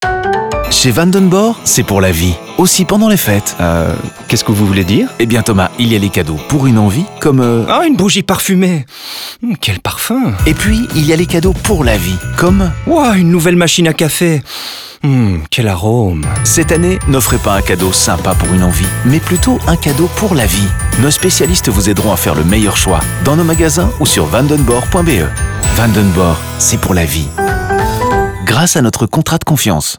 Plusieurs spots radio et une série de vidéos en ligne font également la même comparaison de manière ludique.
Radio